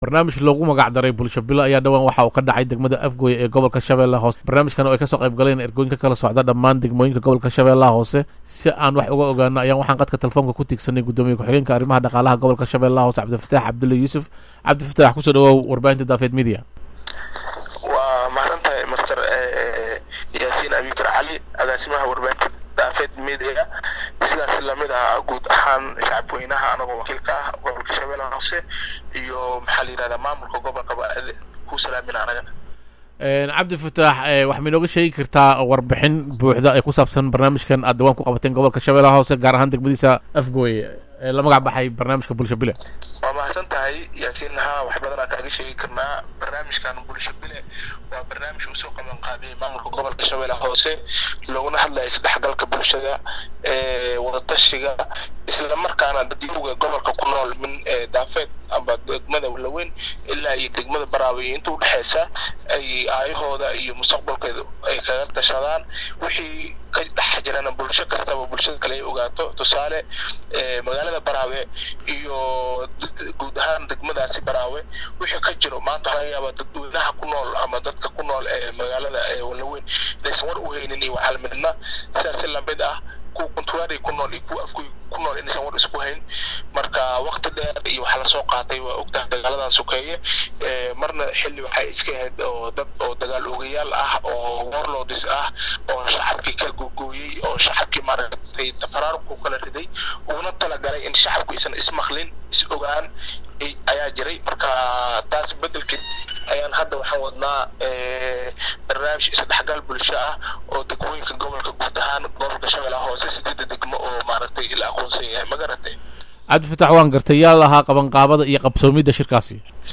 Dhegeyso Gudoomiye Ku Xigeenka Arimaha Dhaqaalaha Gobolka Shabelaha Hoose oo Aan Ka Wareysaney Barnaamij Isbuucii Tegey Lagu Qabtey Degmada Afgooye
Barnaamij loogu magac darey bulsho bile ayaa isbuucii tegey lagu qabtey degmada afgooye ee gobolka shabelaha hoose kaasoo ay kasoo qeyb  galeen argooyin ka kala socda degmooyinka gobolka shabeelaha hoose si aan wax uga ogaano barnaamijkaas iyo waxa uu ku saabsanaa ayaan waxaan qadka telfoonka ku tiigsaney gudoomiye ku xigeenka arimaha dhaqaalaha gobolka shabelaha hoose cabdi fataax cabdulle yusuf.